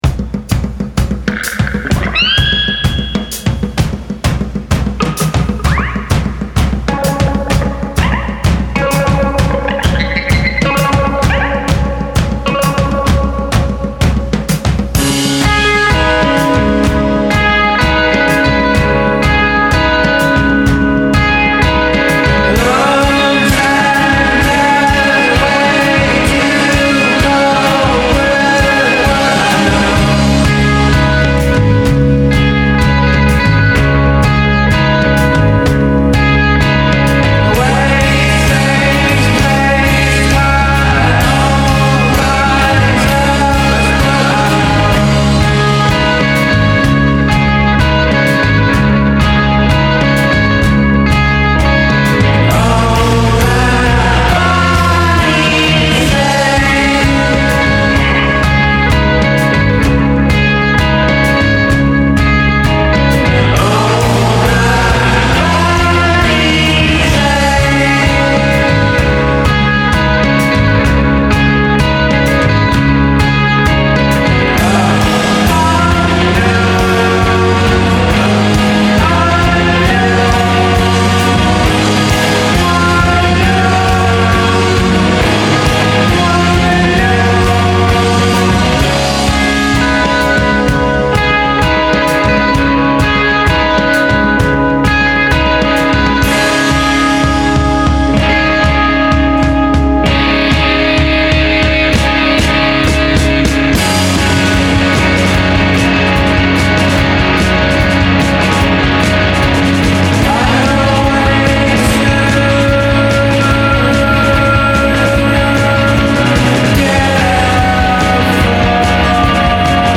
pysch-quintet